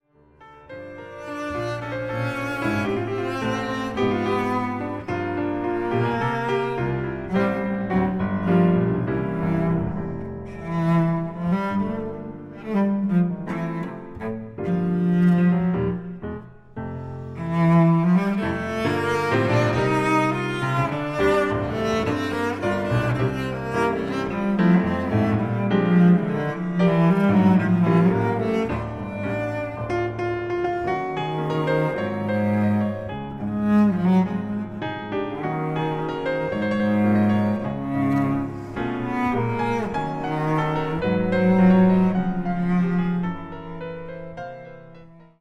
チェロのたおやかな音色による実直な美の結晶であること。
チェロ
ピアノ